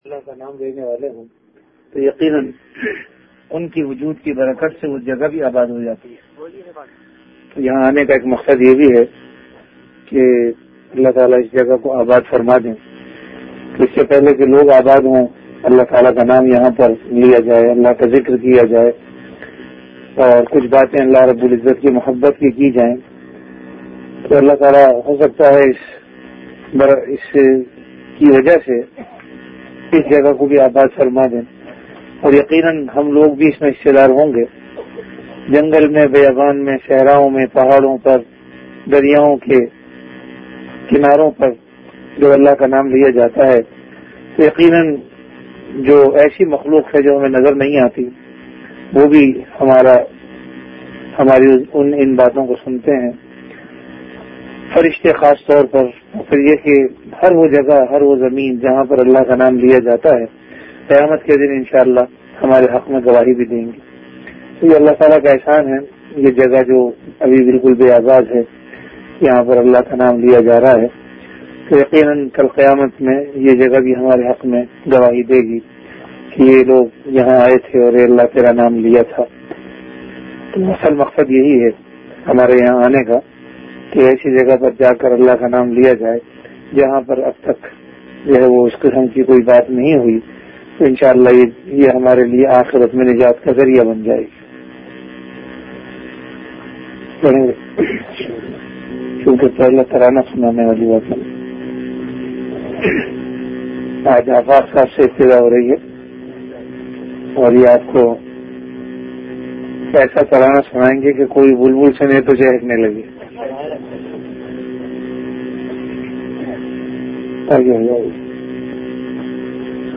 Majlis-e-Zikr · Areesha City, Nothern Bypass
After Isha Prayer